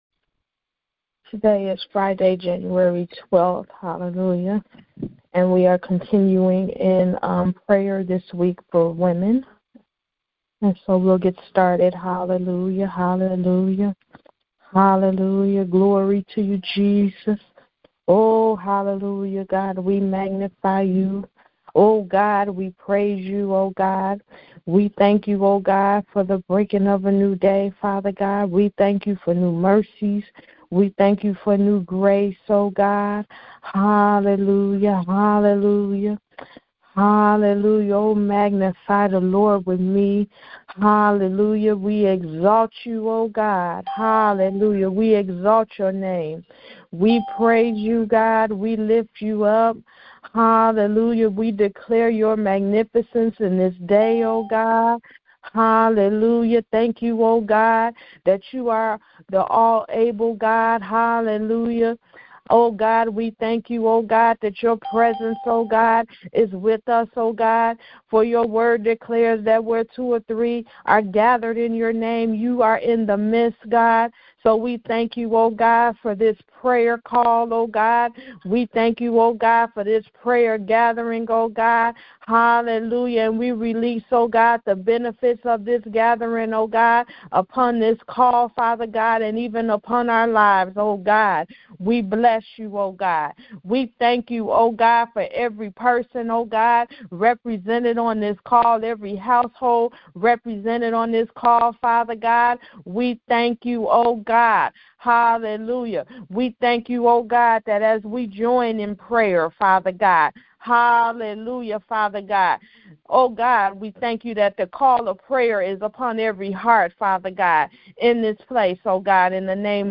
Prayers for women taken from the weekly prayer conference line.